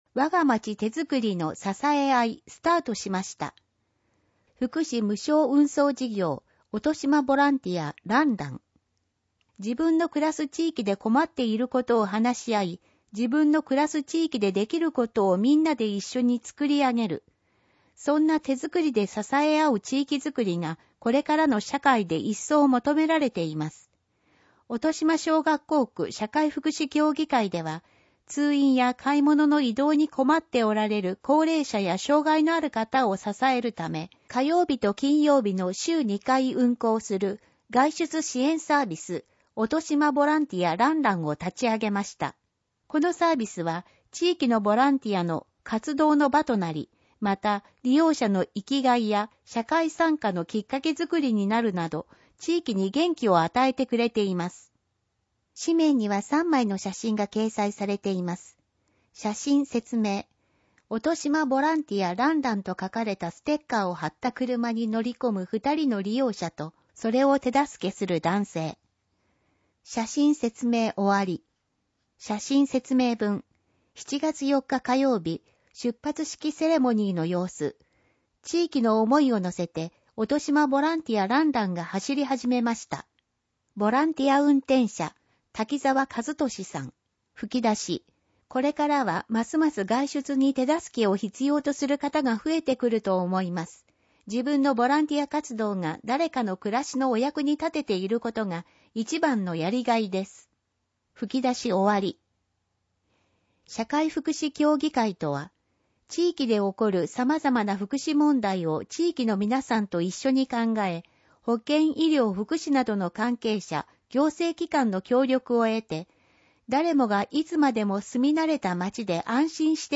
くらしき社協だより第７４号 音訳版